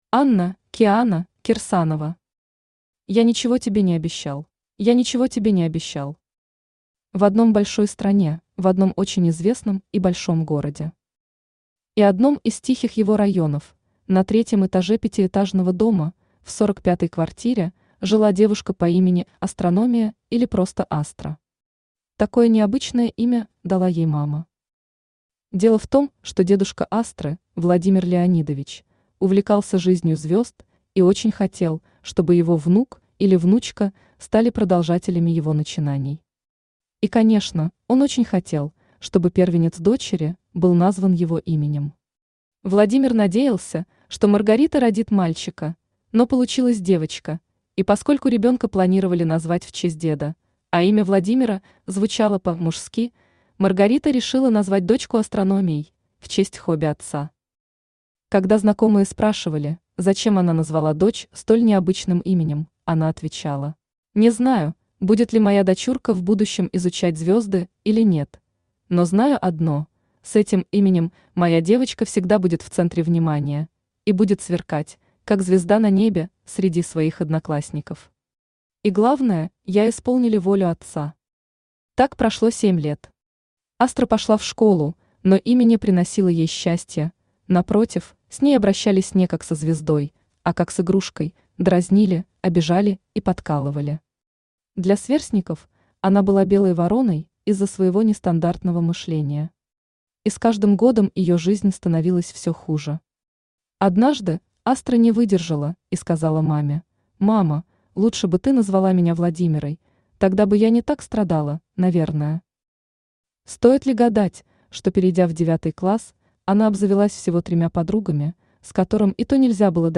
Аудиокнига Я ничего тебе не обещал | Библиотека аудиокниг
Aудиокнига Я ничего тебе не обещал Автор Анна (Киана) Кирсанова Читает аудиокнигу Авточтец ЛитРес.